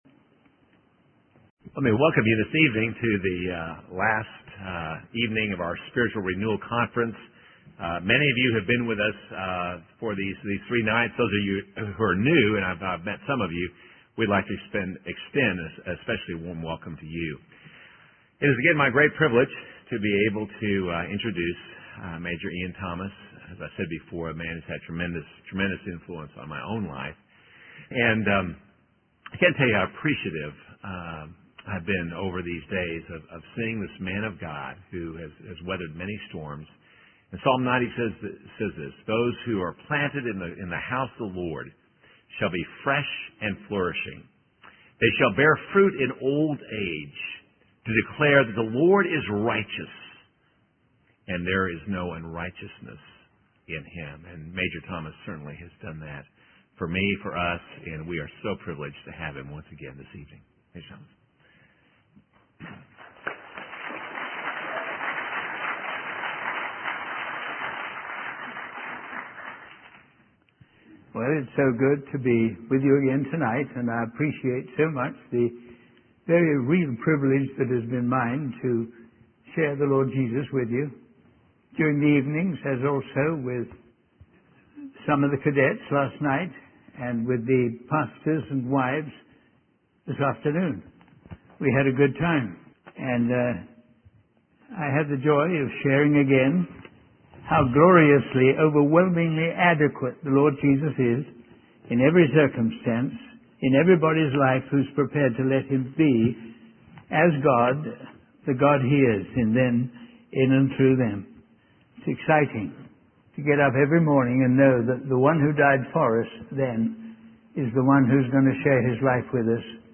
In this sermon, the speaker emphasizes the simplicity and power of the church in action.